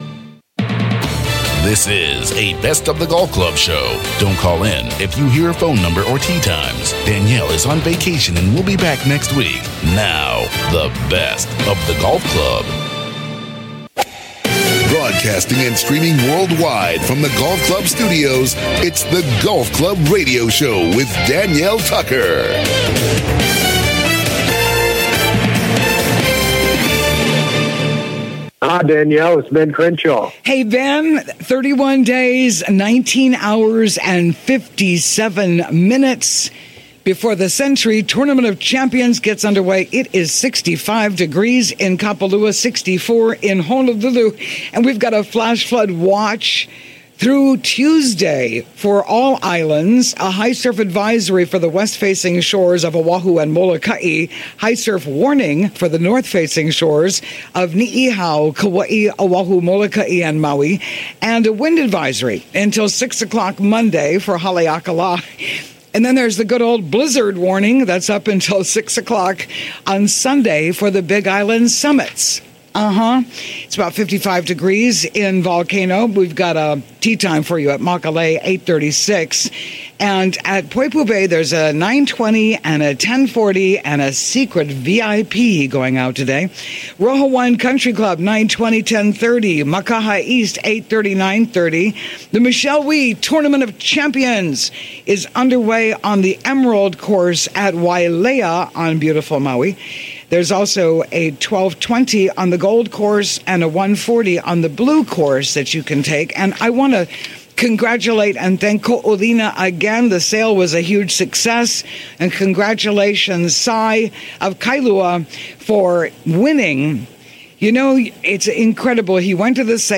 The Golf Club Radio Show� broadcasting world-wide from Hawaii.
Golf Pros, Sports Shrinks, Authors, Mental Coaches and PGA Broadcasters.